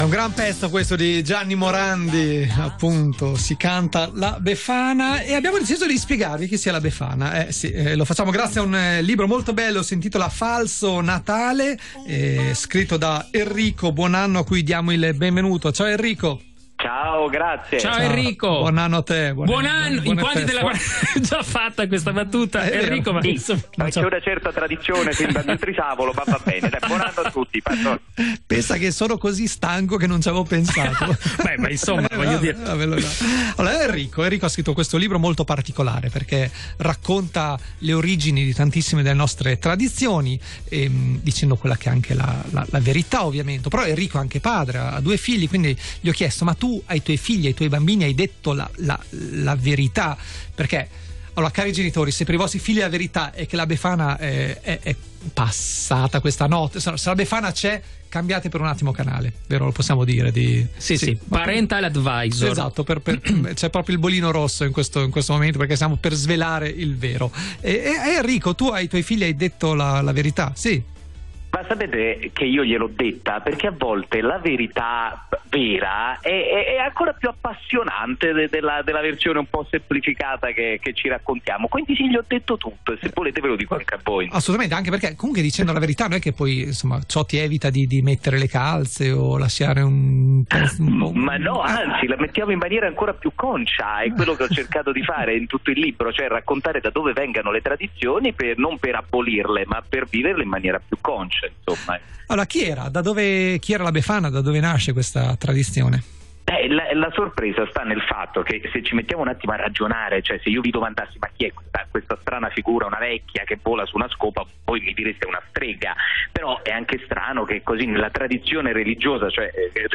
intervistato a I Padrieterni, Radio 24